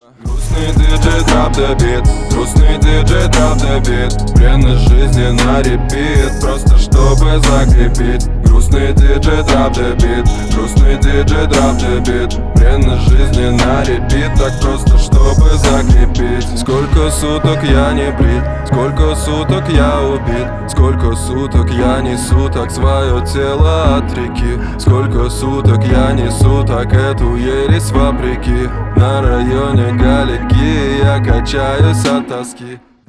• Качество: 320, Stereo
грустные
русский рэп
басы